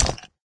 icestone.ogg